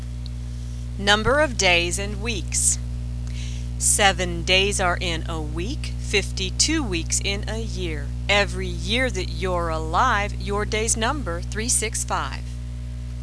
Ditty Number of Days and Weeks